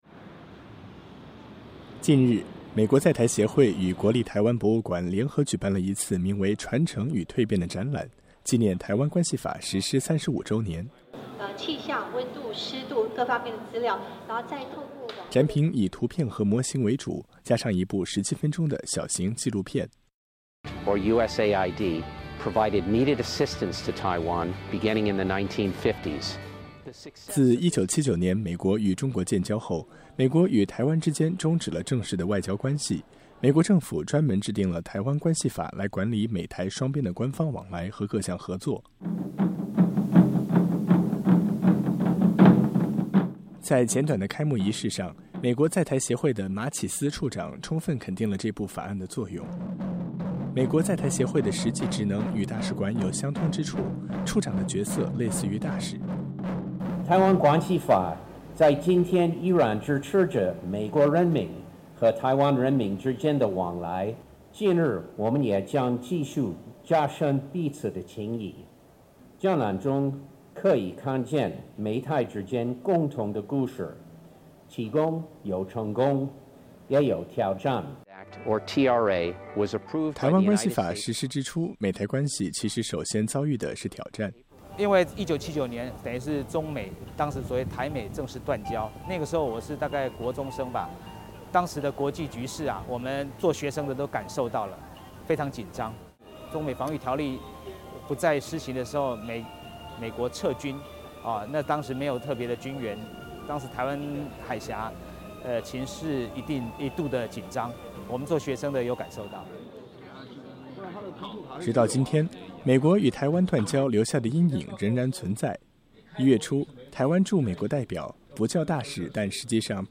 台北 —
尽管缺少正式的名分而且经常会遇到挑战，但这些新北市小学生们演奏的音乐很大程度上反映出美台关系实质上的和谐。